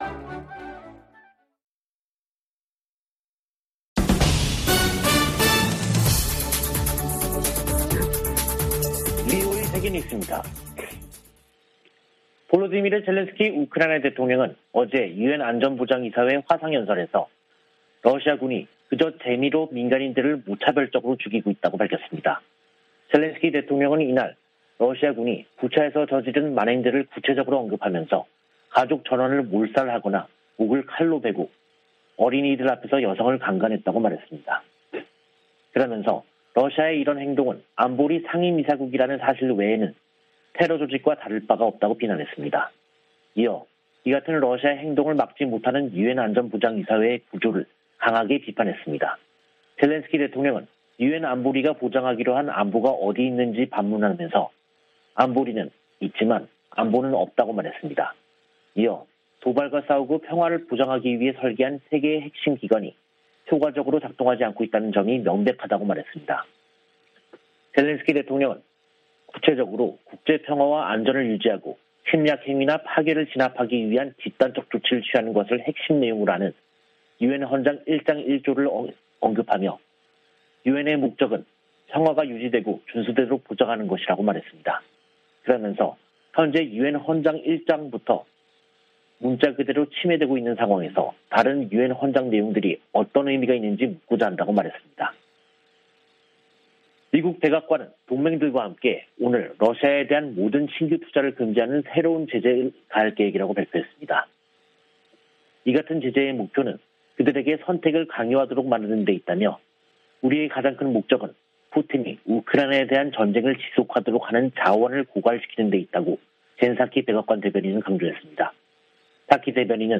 VOA 한국어 간판 뉴스 프로그램 '뉴스 투데이', 2022년 4월 6일 2부 방송입니다. 미 국무부는 탄도미사일 발사가 북한을 더욱 고립시키고 한반도 안정을 해치는 행위일 뿐이라고 지적했습니다. 북한의 탄도미사일 역량이 미 본토에까지 실질적인 위협이 되고 있다고 미 합참의장이 평가했습니다. 백악관 국가안보보좌관은 미국을 방문한 한미정책협의대표단을 면담하고 정상회담 조기 개최와 전략자산 배치 등에 대해 논의한 것으로 알려졌습니다.